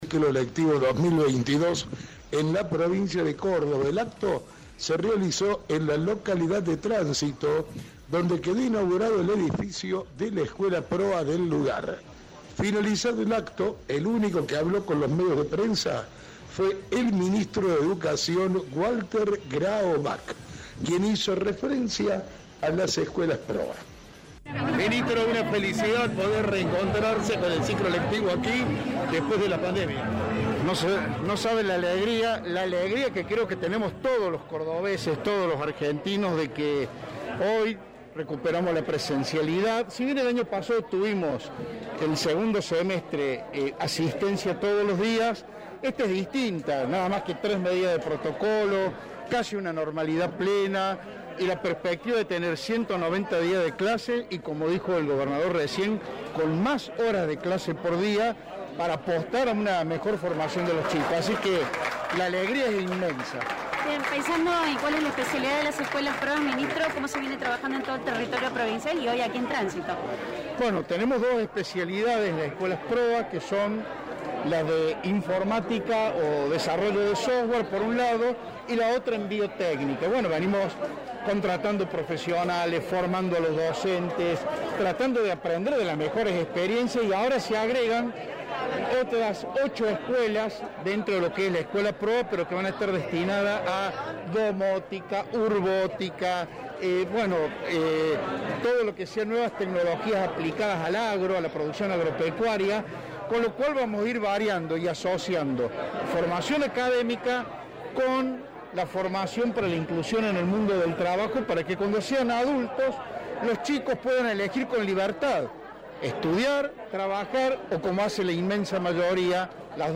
Walter Grahovac, Ministro de Educación de Córdoba, dialogó con los medios en el marco de la inauguración del ciclo lectivo 2022 en Tránsito.